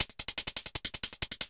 Hats 09.wav